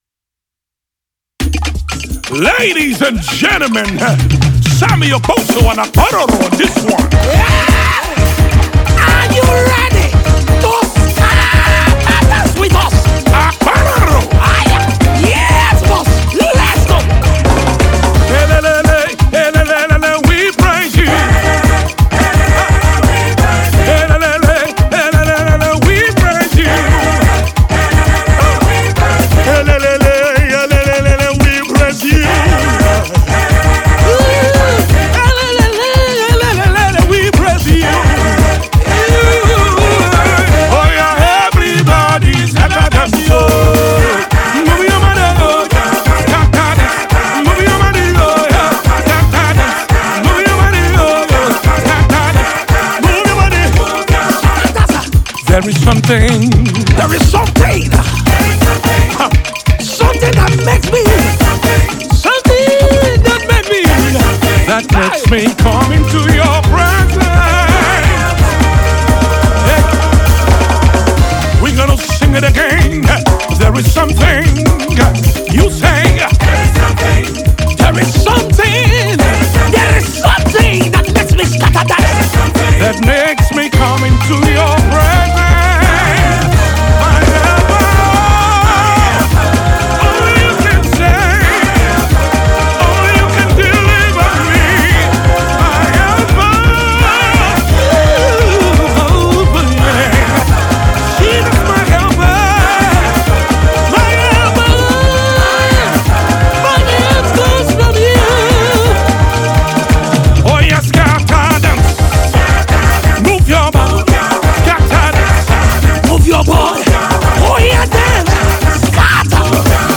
radical praise single